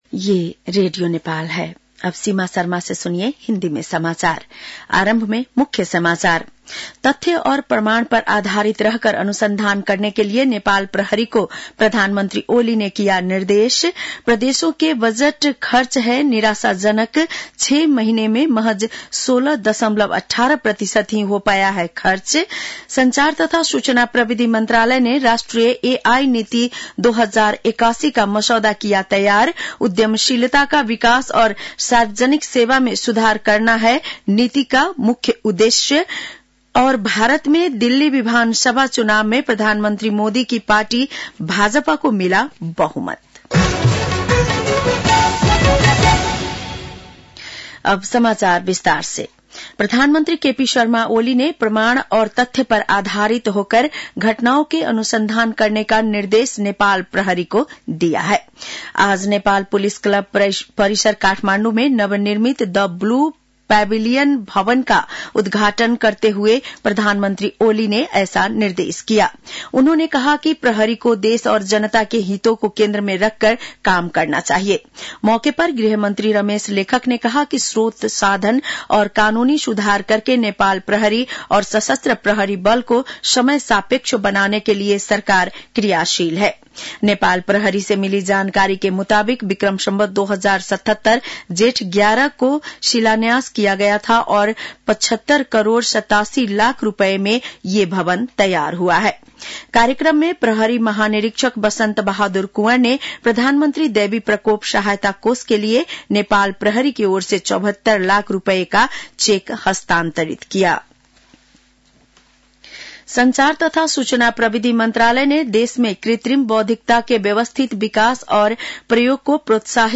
बेलुकी १० बजेको हिन्दी समाचार : २७ माघ , २०८१